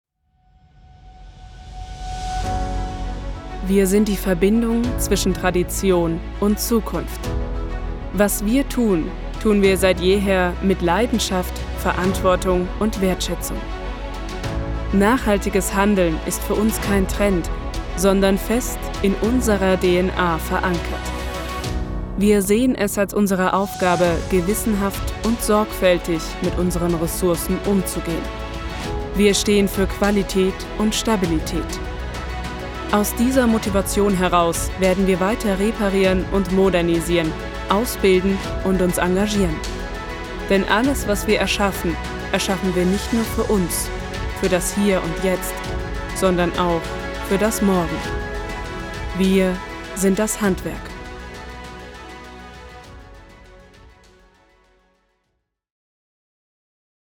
Eigenes prof. Studio vorhanden (Neumann TLM 103)
Sprechprobe: Industrie (Muttersprache):